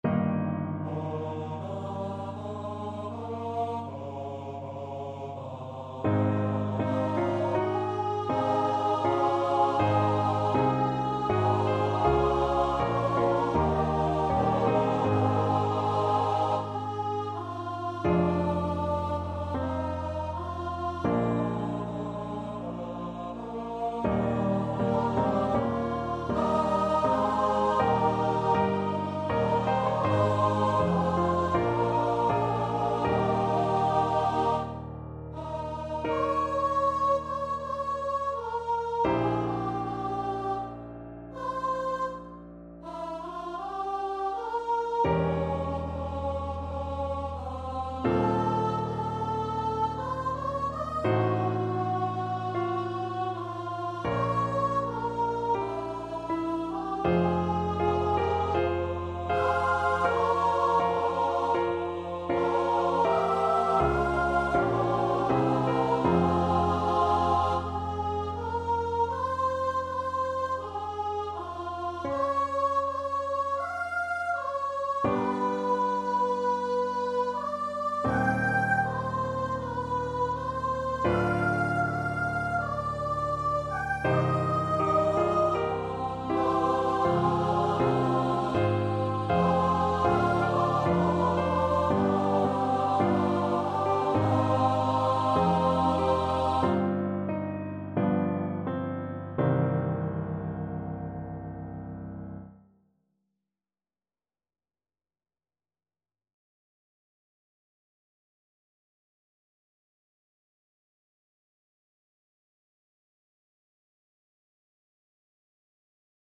Nun ist der Her zur Ruh gebracht (St. Matthew Passion) Choir version
Choir  (View more Intermediate Choir Music)
Classical (View more Classical Choir Music)